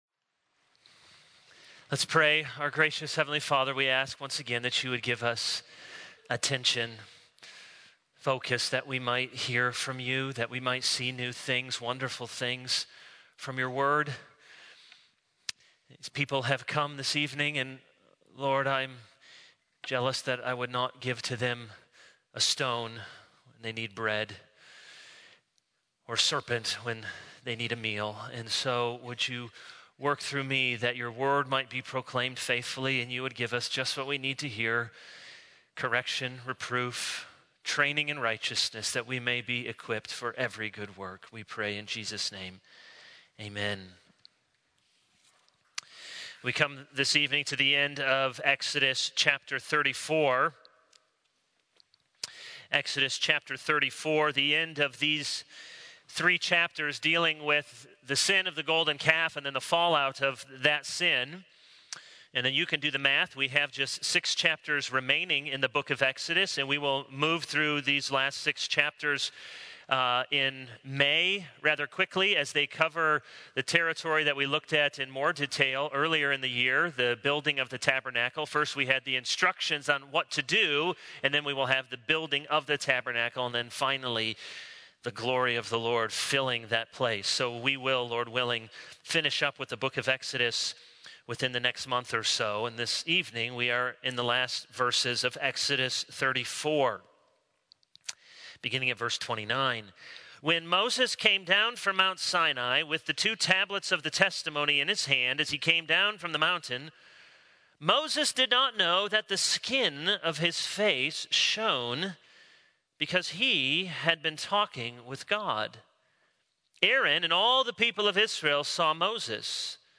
This is a sermon on Exodus 34:29-35.